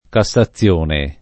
cassazione [ ka SS a ZZL1 ne ]